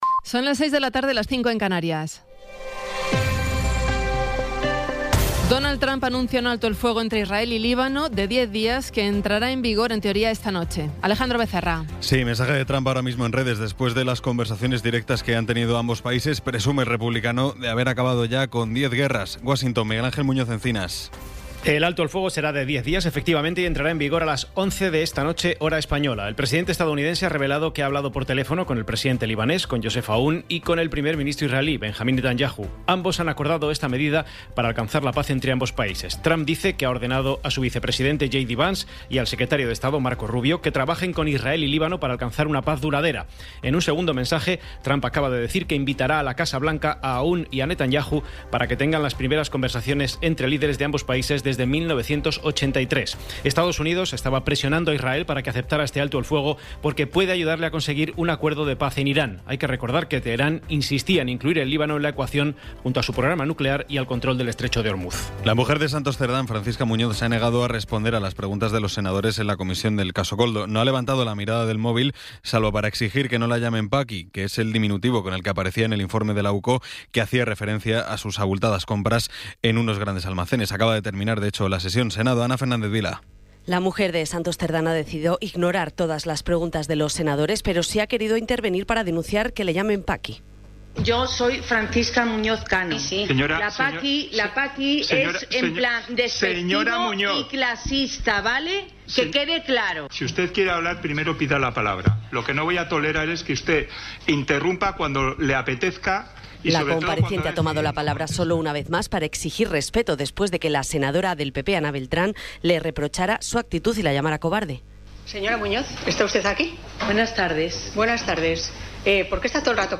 Resumen informativo con las noticias más destacadas del 16 de abril de 2026 a las seis de la tarde.